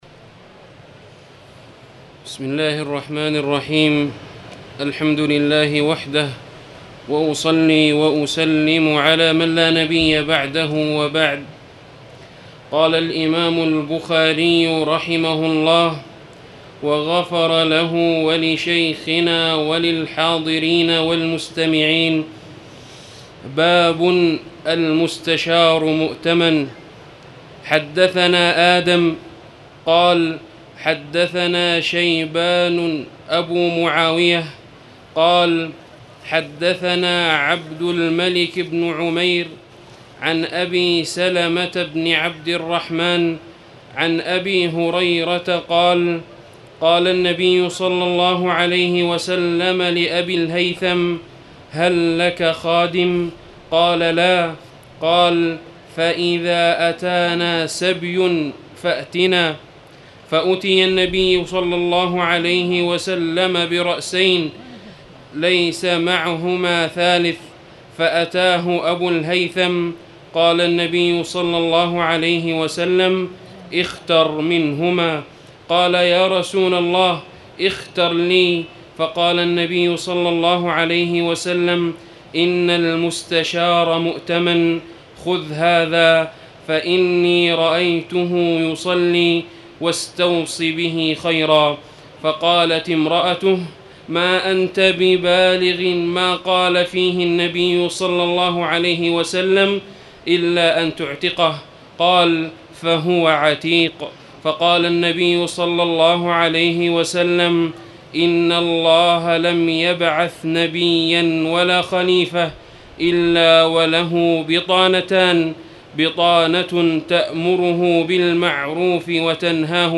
تاريخ النشر ١١ ربيع الثاني ١٤٣٨ هـ المكان: المسجد الحرام الشيخ: فضيلة الشيخ د. خالد بن علي الغامدي فضيلة الشيخ د. خالد بن علي الغامدي باب المستشار مؤتمن